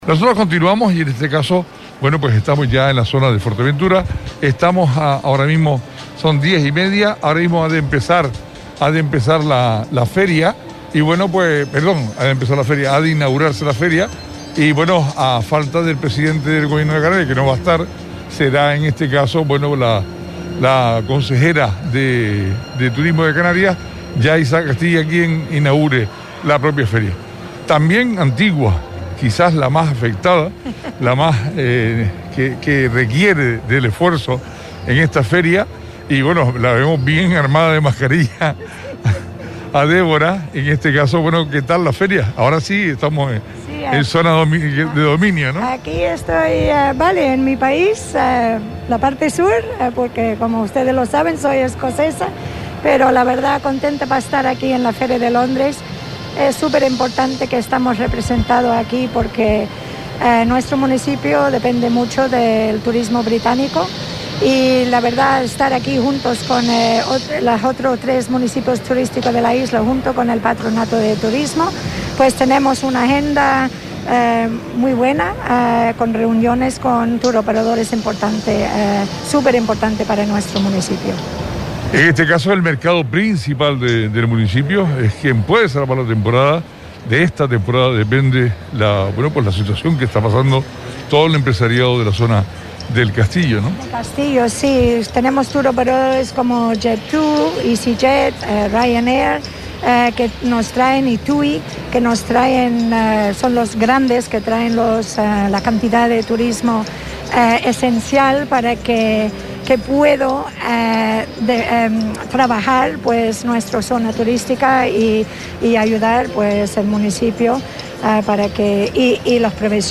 Deborah Edingtton concejala de Turismo de Antigua
Entrevistas
La emisora de Radio Sintonía se encuentra en Londres para retransmitir en directo todas las novedades que van surgiendo con respecto a Fuerteventura en World Travel Market.